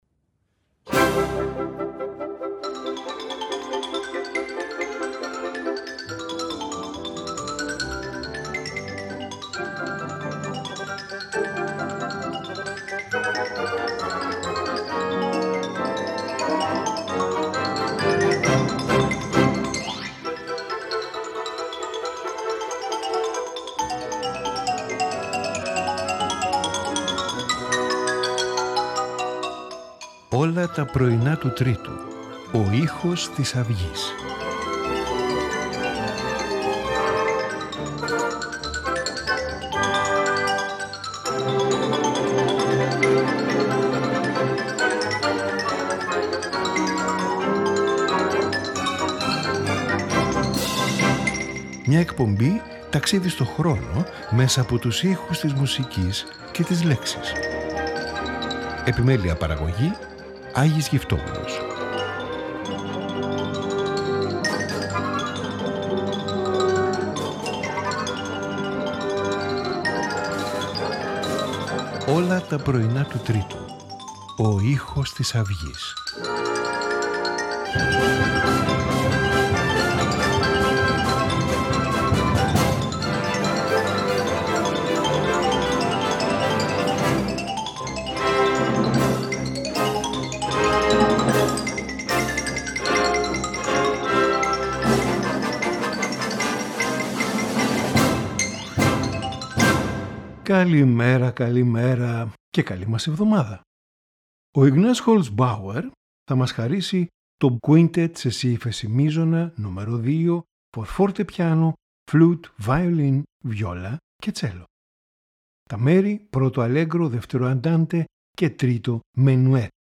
String Quartet in G major
Divertimento in B-flat major